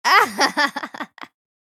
female_laugh1.ogg